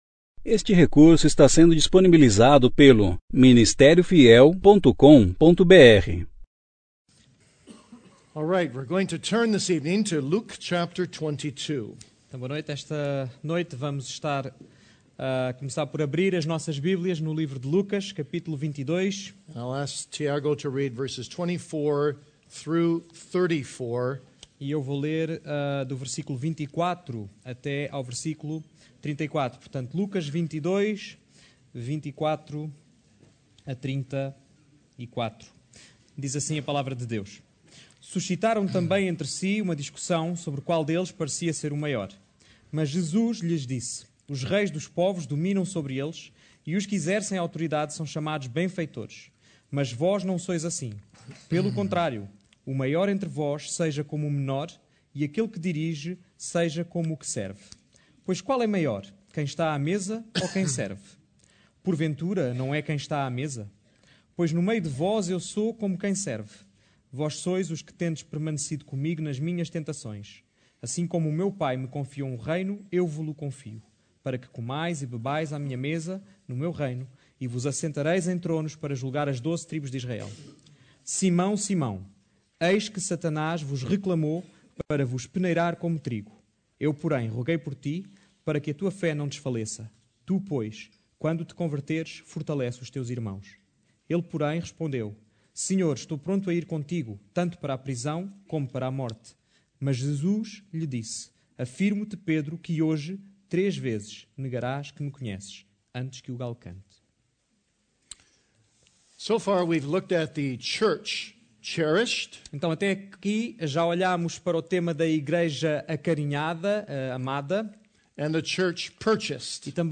Conferência: 16ª Conferência Fiel para Pastores e Líderes – Portugal Tema: Reedifiquem esta casa!